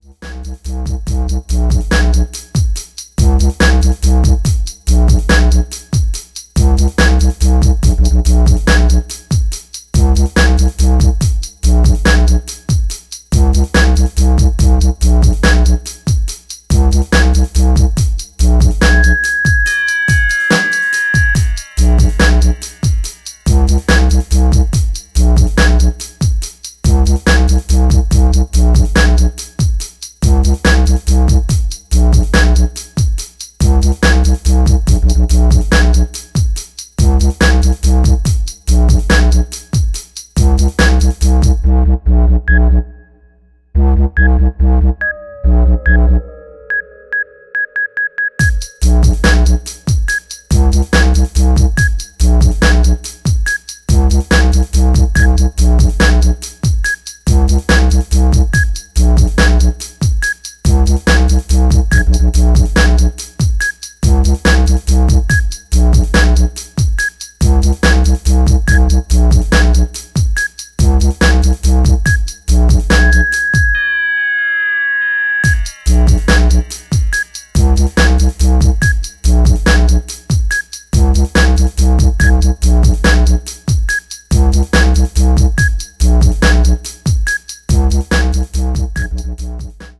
Styl: Dub/Dubstep